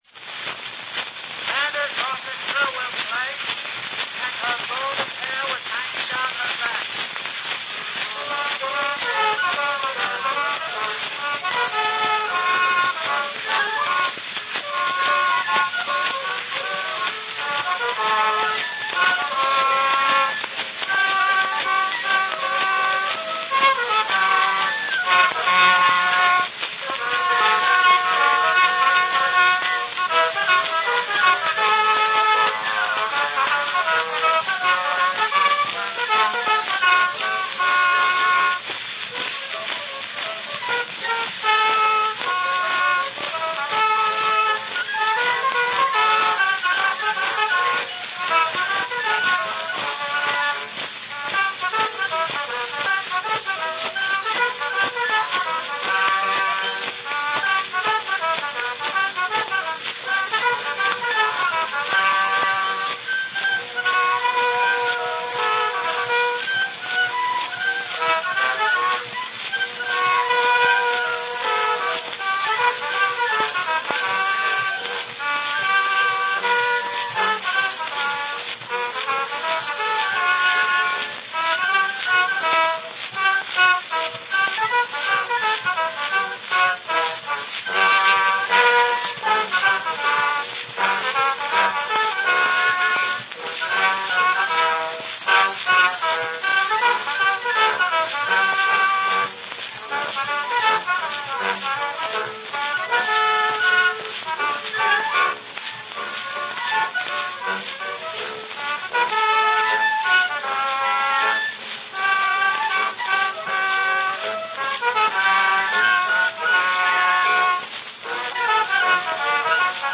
RealAudio file from a wax cylinder recording